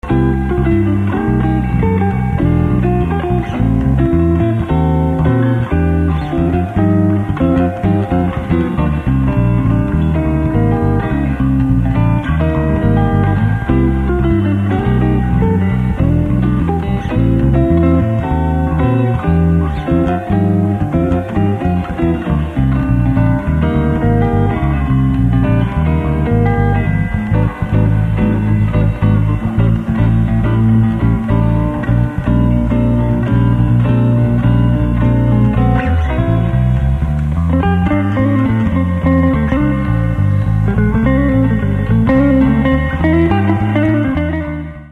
Jazz, chorinho e bossa nova em Laranjeiras
Trio
A grande diferença que provavelmente causará um certo incômodo aos ouvidos mais atentos é a nova versão jazzística da música.
contrabaixoo
sax e flauta